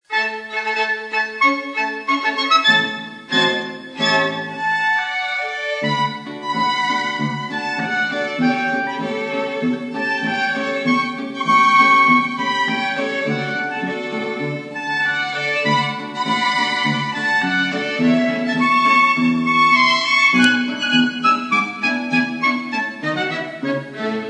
Aufgenommen am 1. - 2. Mai 2000 in Wien